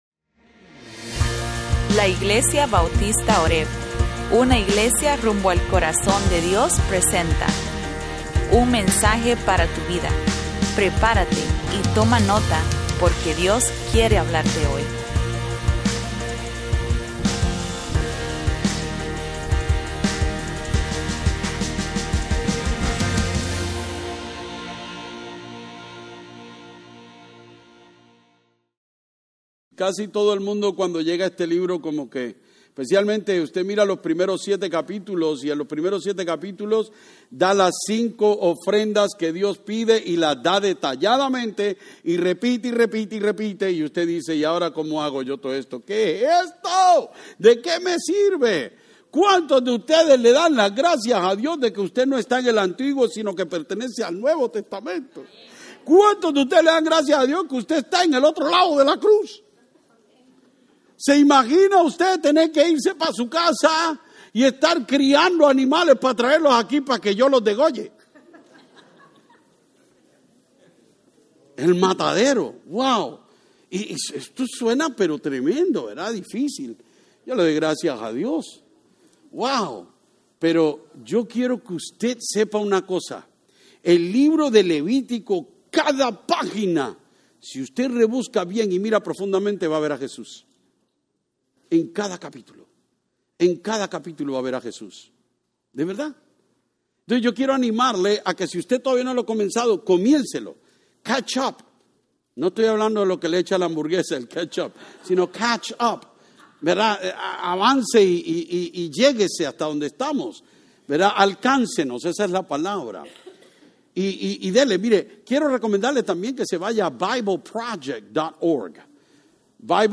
Sermones-8_17_25-spanish.mp3